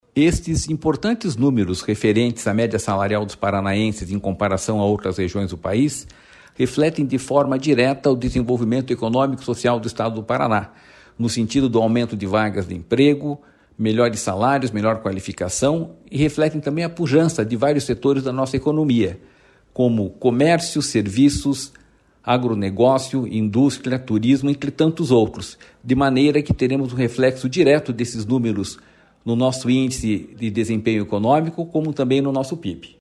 Sonora do diretor-presidente do Ipardes, Jorge Callado, sobre o aumento na renda per capita do paranaense